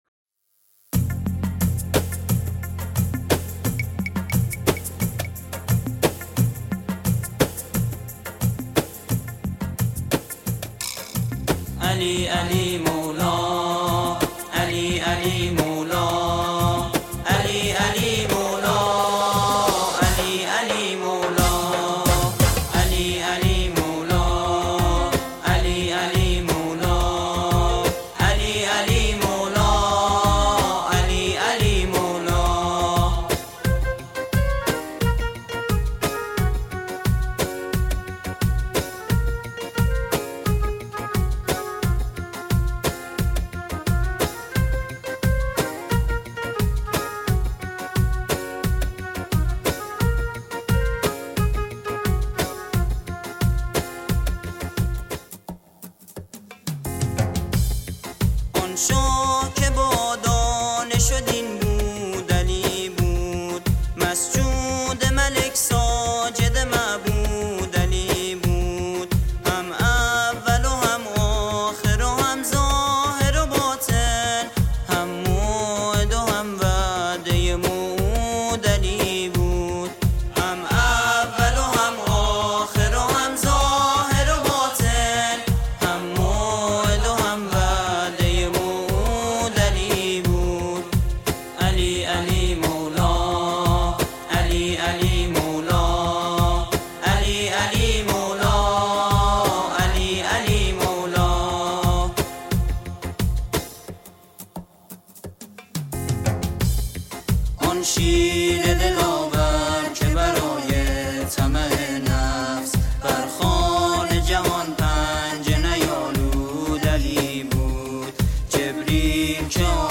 سرودی جشن‌آمیز و حماسی
ژانر: سرود